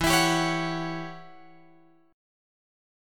E Minor 6th Add 9th